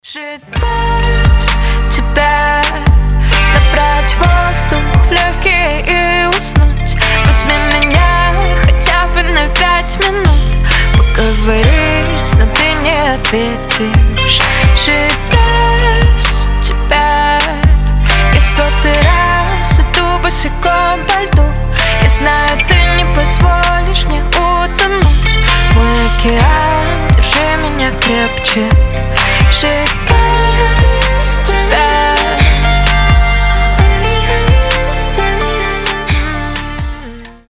• Качество: 320, Stereo
грустные
спокойные
красивый женский голос
печальные